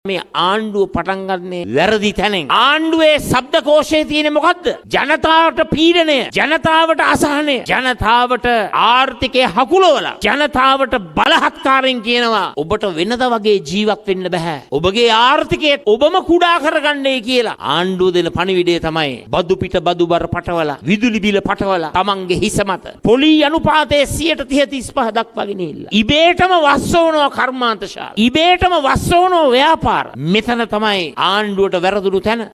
මේ එහිදි වැඩිදුරටත් අදහස් දැක්වූ විපක්ෂ නායක සජිත් ප්‍රේමදාස මහතා